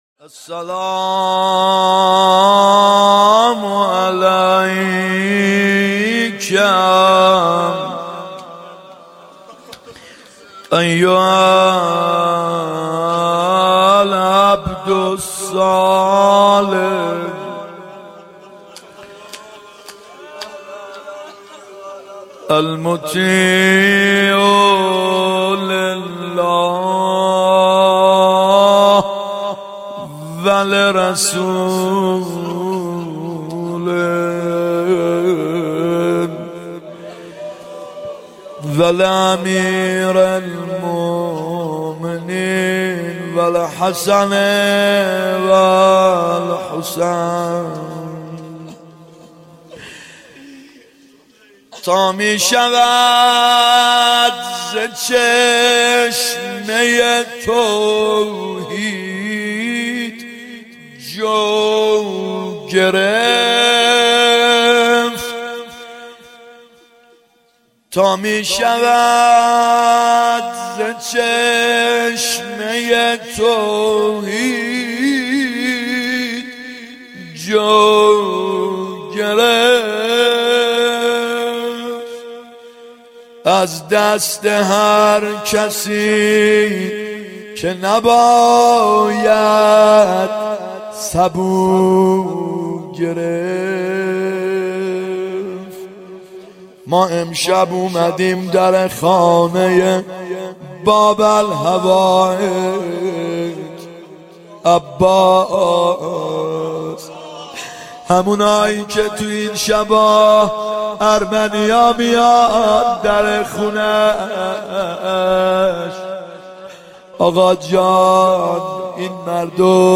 محرم 91 ( هیأت یامهدی عج)